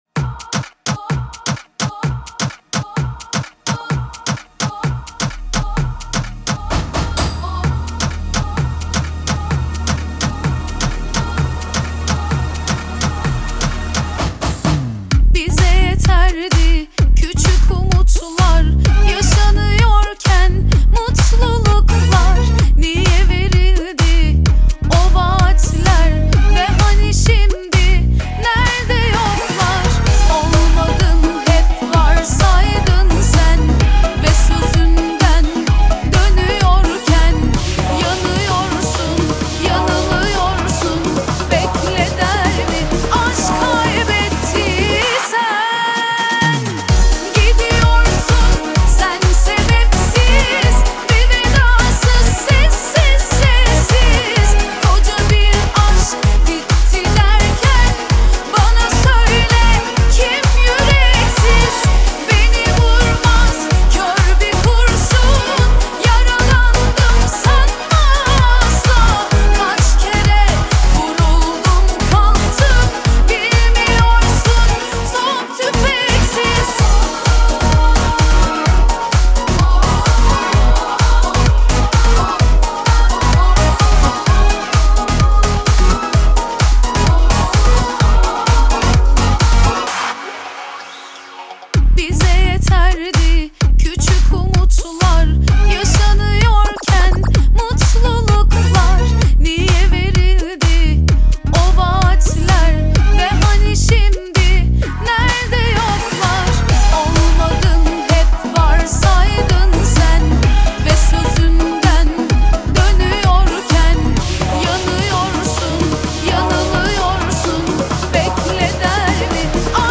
آهنگ تورکی